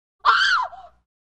Звуки криков
7. Короткий крик девушки